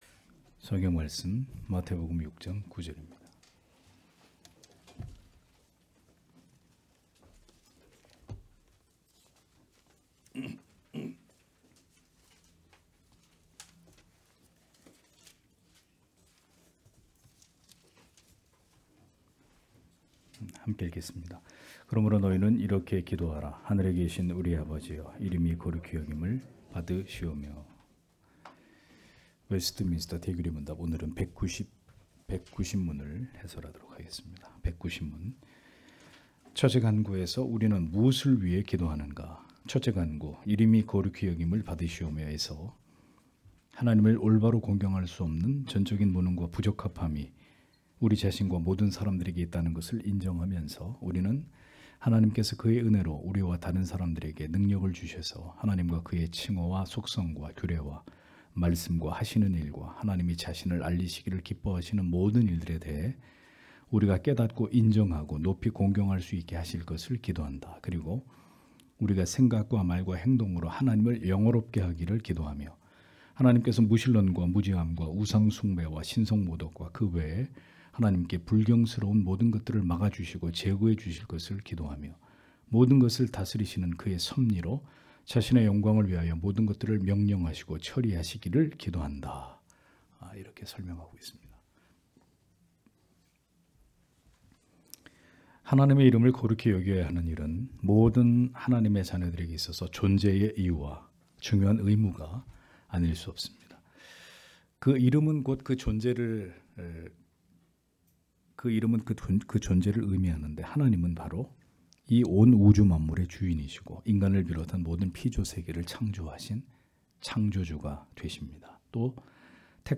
주일오후예배 - [웨스트민스터 대요리문답 해설 190] 190문) 첫 기원에서 우리는 무엇을 기도하는가? (마태복음 6장 9절)